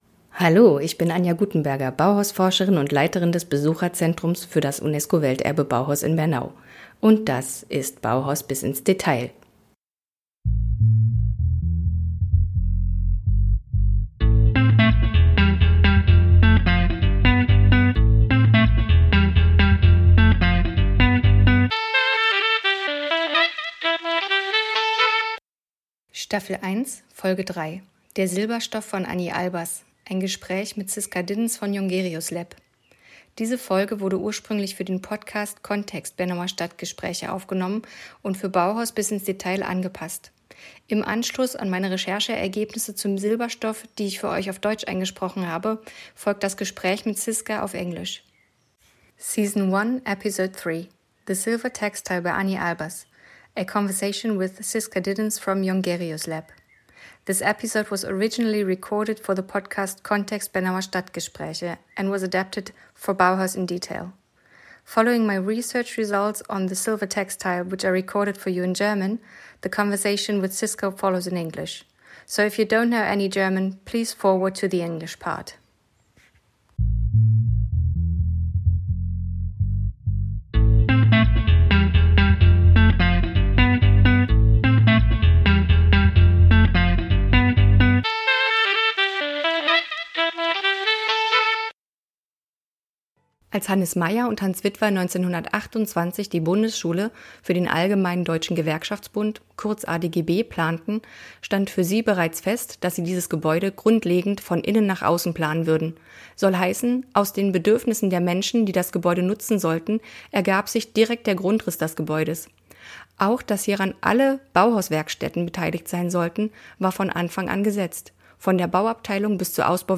Im Gespräch
A conversation